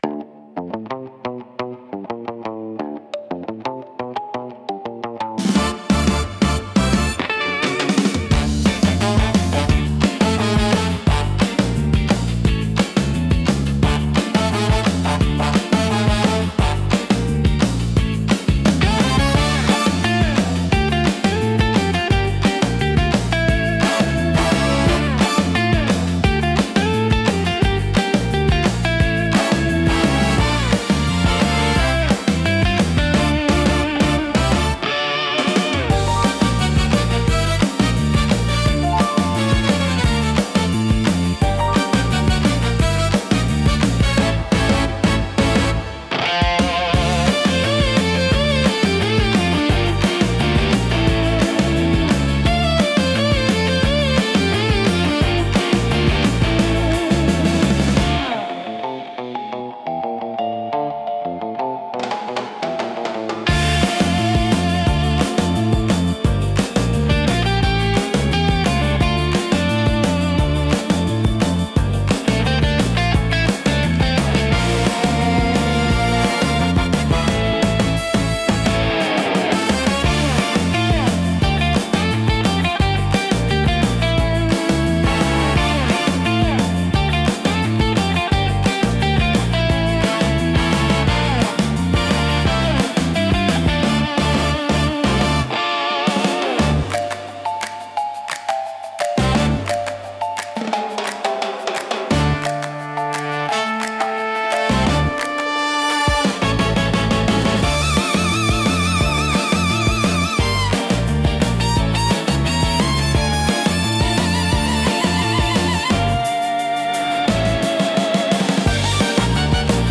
不穏さとエネルギーが同居し、聴く人の集中力を高めつつドキドキ感を作り出します。